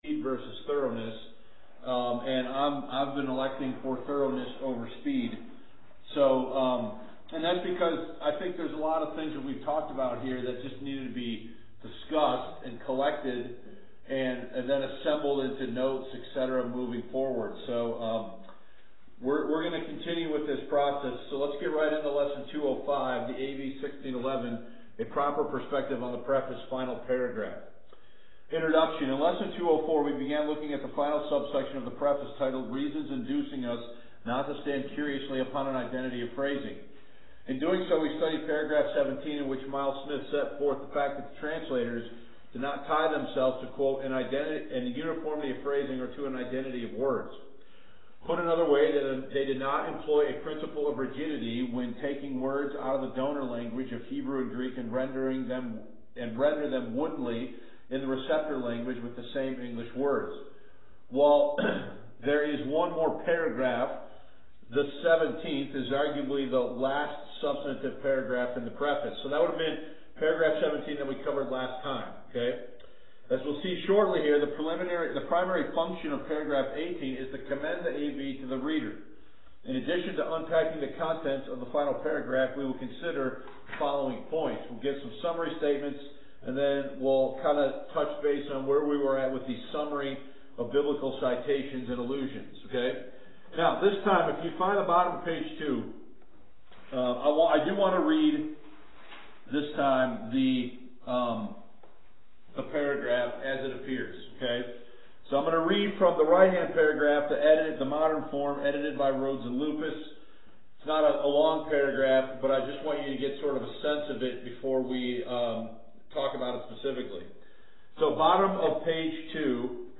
Lesson 205 The AV 1611: Producing A Proper Perspective on the Preface (Final Paragraph)